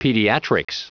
Prononciation du mot pediatrics en anglais (fichier audio)
Prononciation du mot : pediatrics